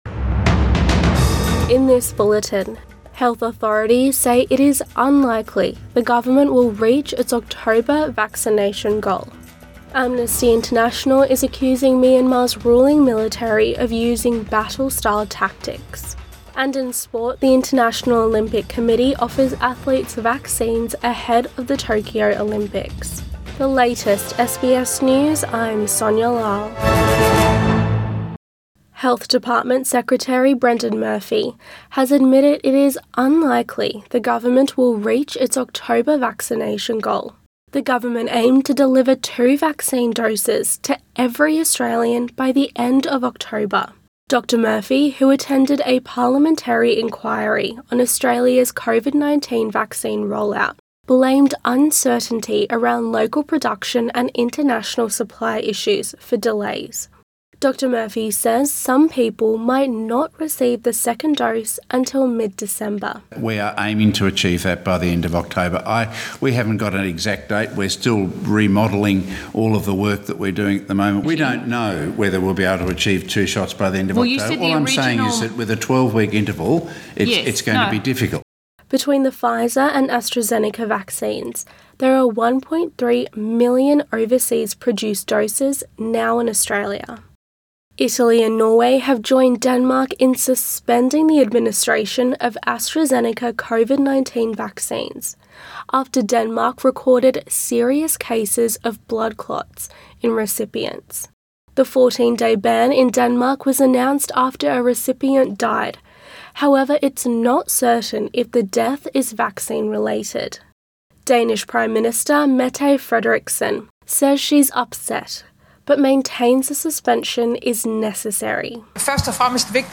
AM bulletin 12 March 2021